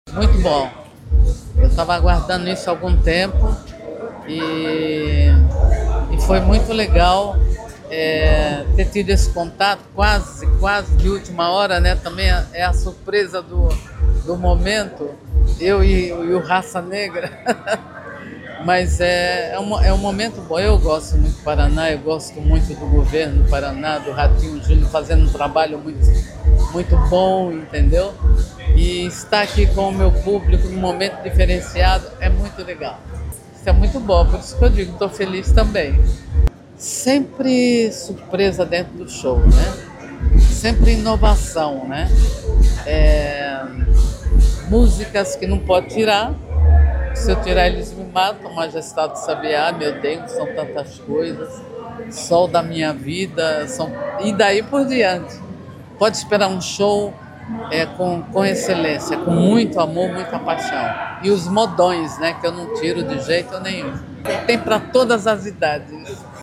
Sonora da cantora Roberta Miranda, sobre o show deste sábado, em Pontal do Paraná, pelo Verão Maior Paraná